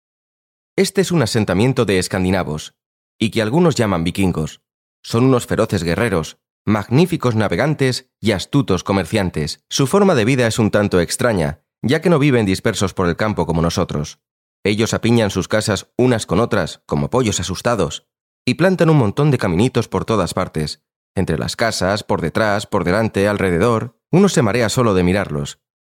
Sprecher
Kommerziell, Natürlich, Vielseitig, Zuverlässig, Corporate
Audioguide